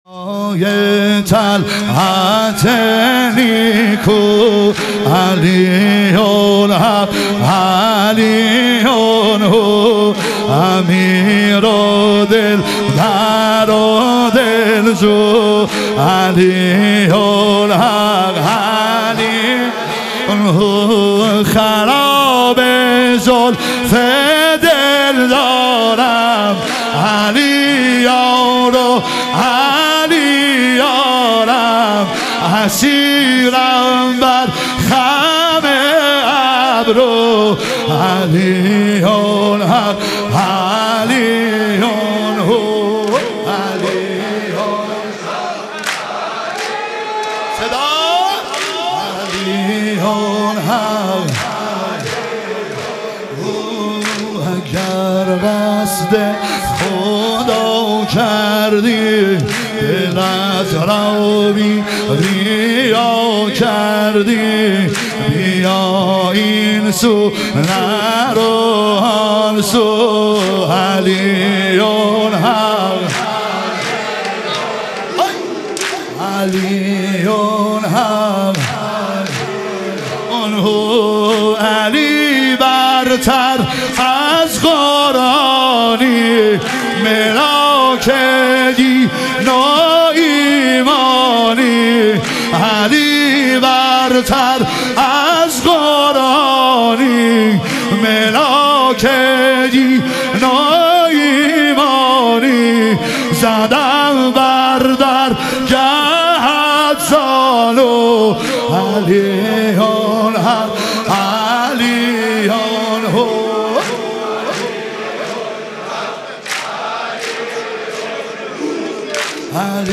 لیالی قدر و شهادت امیرالمومنین علیه السلام - واحد